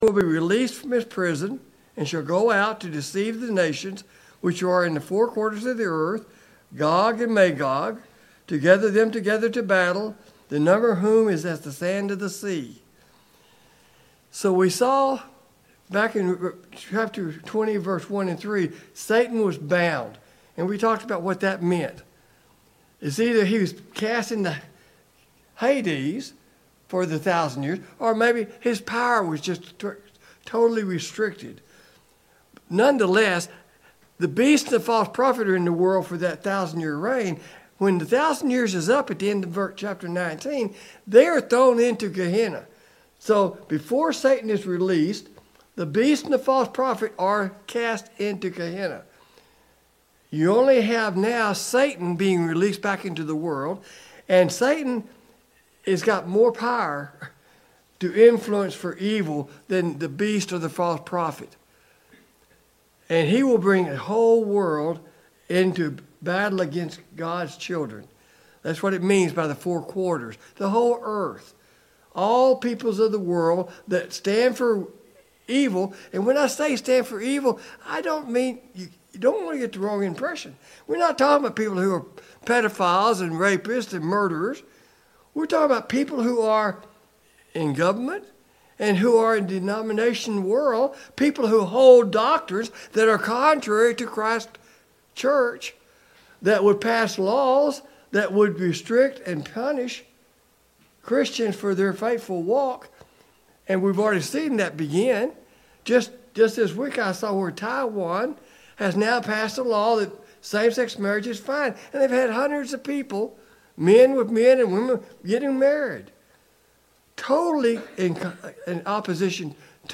Service Type: Sunday Morning Bible Class Topics: Plan of Salvation , Salvation